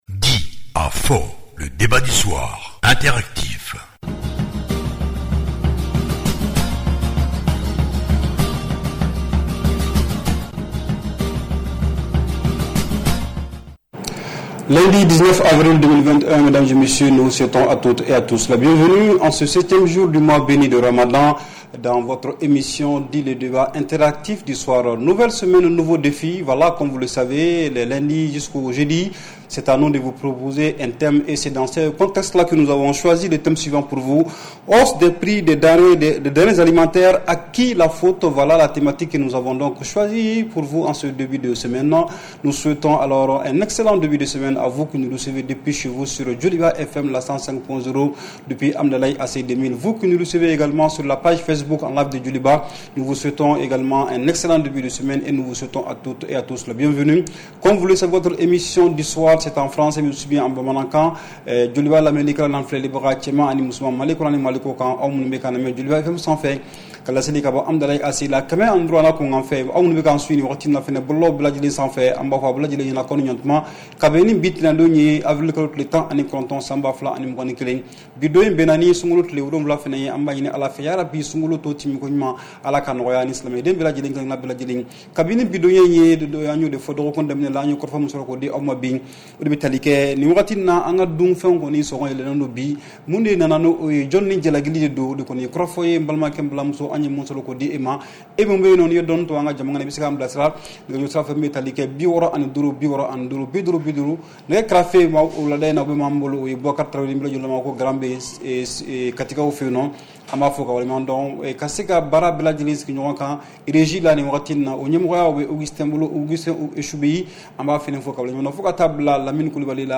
REPLAY 19/04 – « DIS ! » Le Débat Interactif du Soir